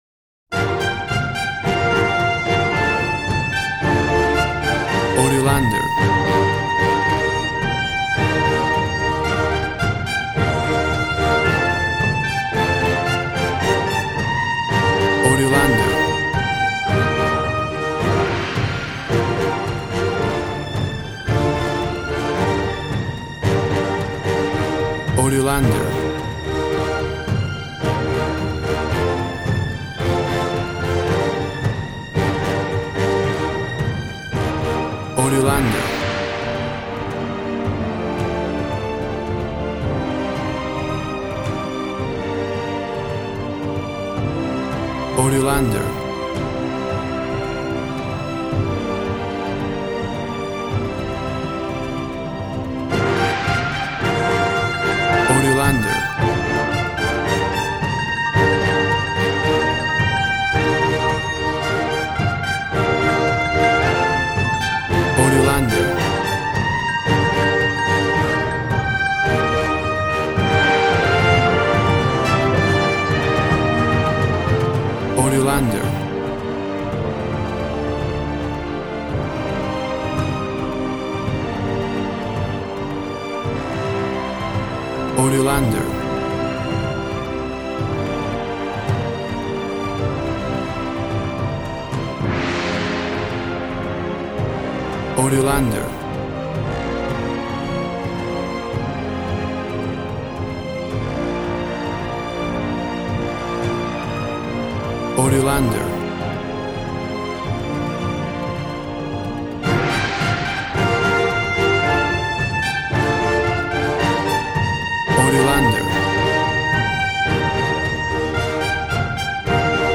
WAV Sample Rate 16-Bit Stereo, 44.1 kHz
Tempo (BPM) 111